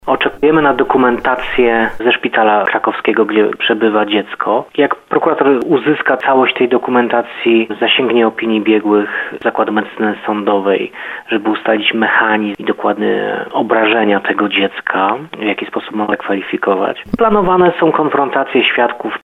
Planowane są konfrontacje świadków – mówi Bartosz Gorzula , Prokurator Rejonowy w Nowym Sączu.